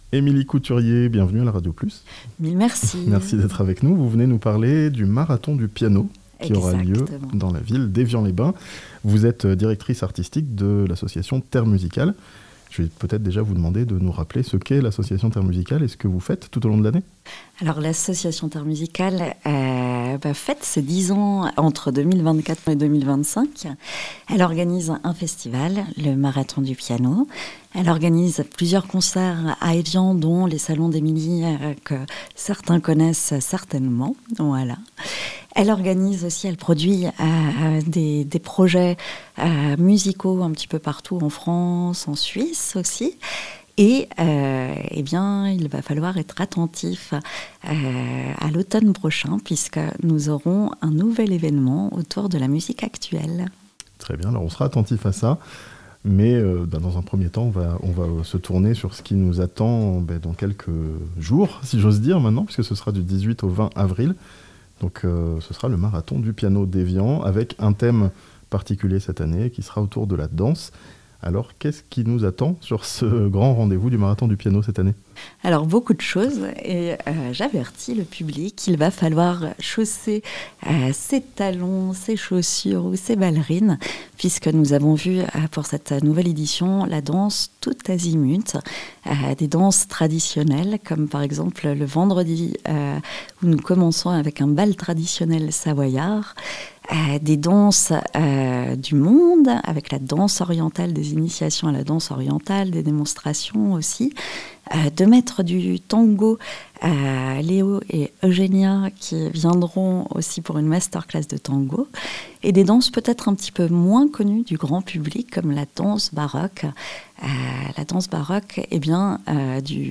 Du 18 au 20 avril, piano et danse seront à l'honneur à Evian (interview)